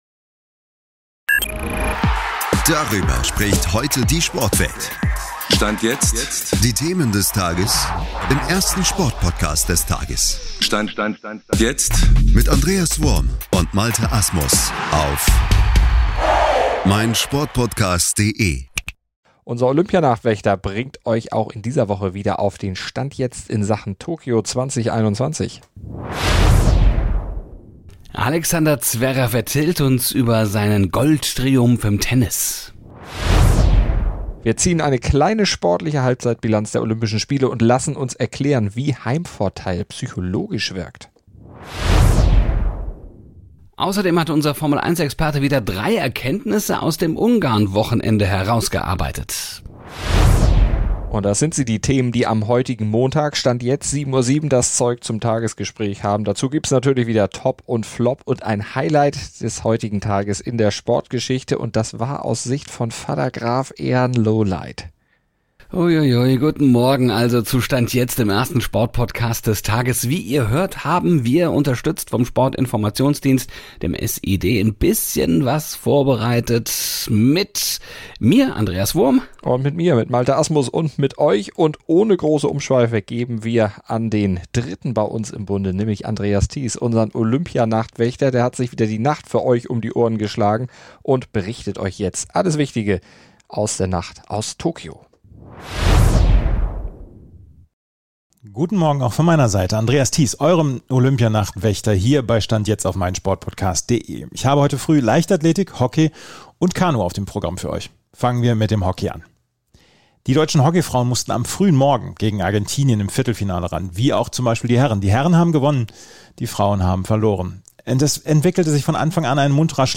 Viel Olympia … aber eben nicht nur - Stand Jetzt begrüßt euch zum Wochenstart mit einem bunten Themenmix aus der Sportwelt. Und weder Formel 1, noch der deutsche Fußball werden ausgespart. Aber natürlich gibt es ein aktuelles Tokio-Update und Alexander Zverev im Interview.